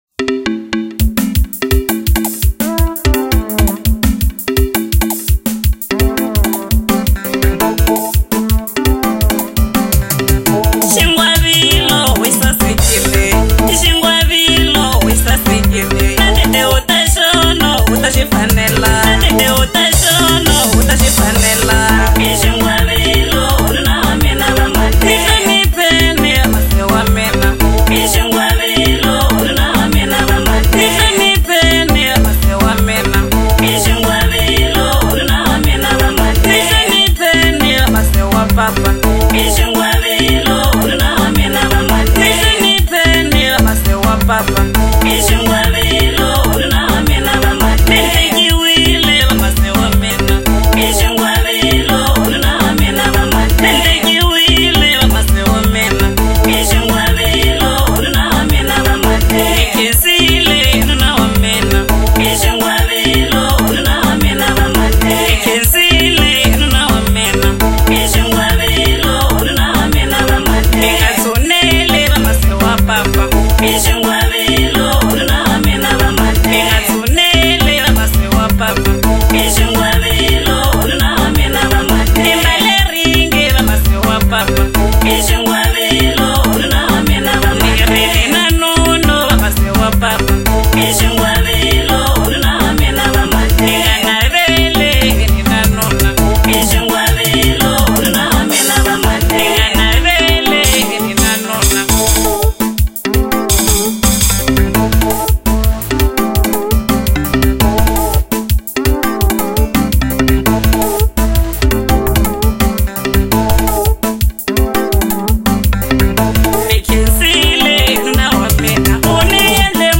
04:11 Genre : Xitsonga Size